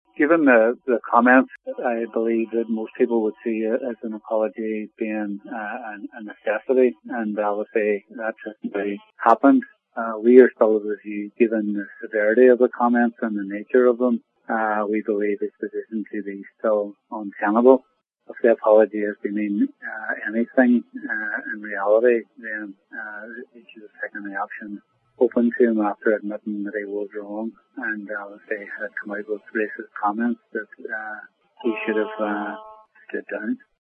Sinn Fein Councillor Paul Flemming says Councillor Carr’s position as an elected representative is untenable: